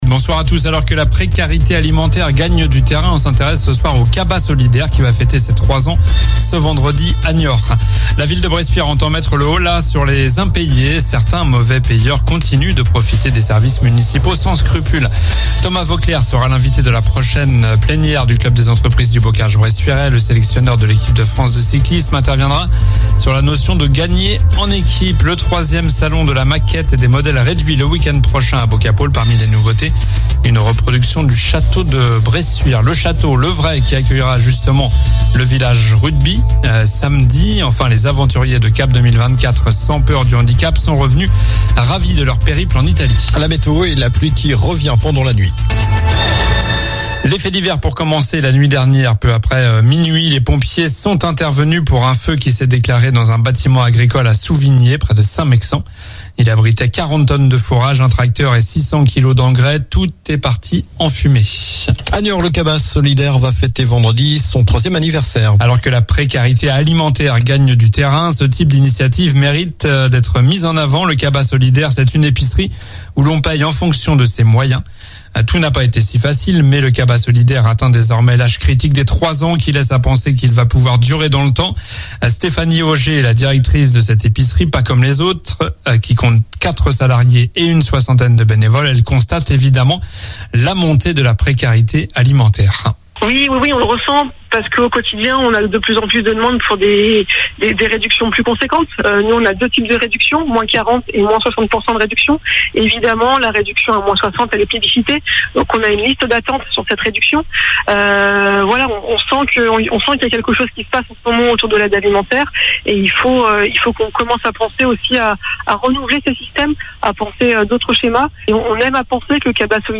Journal du mercredi 20 septembre (soir)